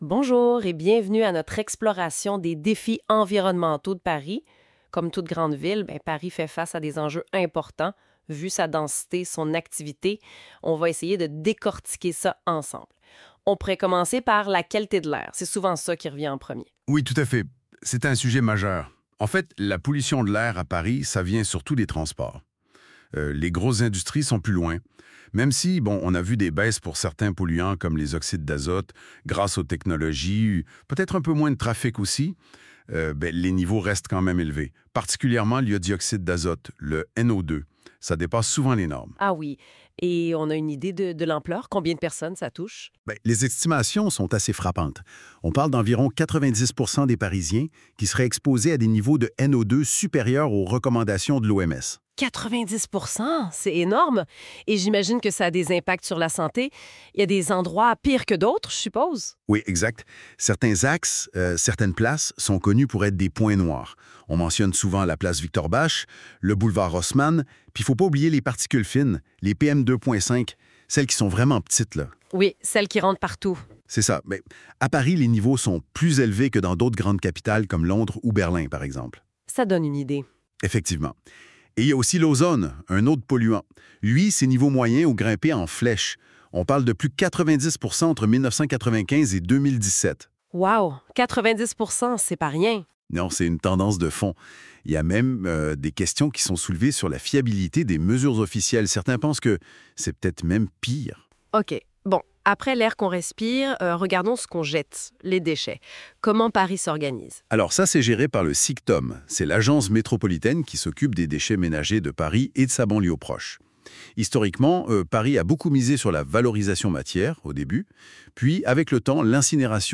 Québécois